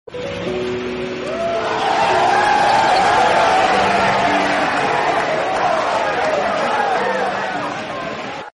lomba tarik tambang ibu-ibu grup